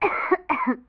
cough2.wav